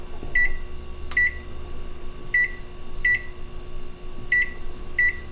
实地录音 " 八王子四月
标签： 场记录
声道立体声